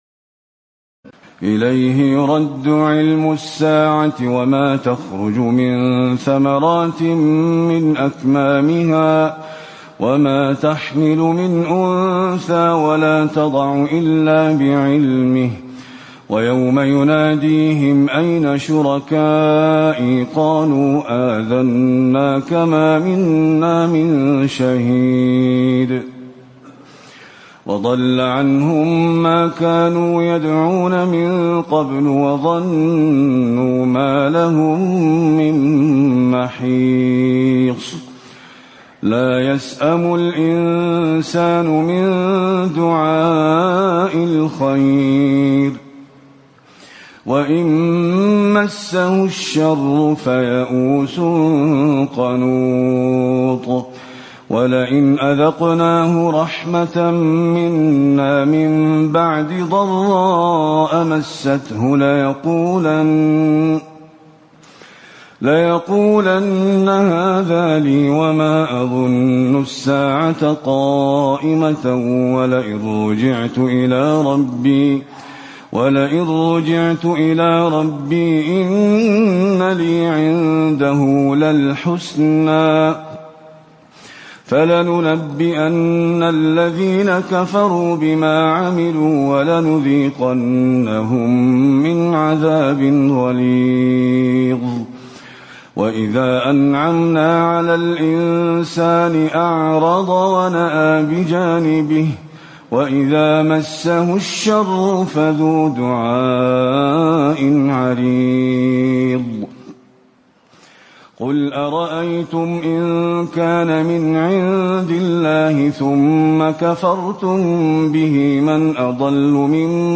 تراويح ليلة 24 رمضان 1437هـ من سور فصلت (47-54) و الشورى و الزخرف (1-25) Taraweeh 24 st night Ramadan 1437H from Surah Fussilat and Ash-Shura and Az-Zukhruf > تراويح الحرم النبوي عام 1437 🕌 > التراويح - تلاوات الحرمين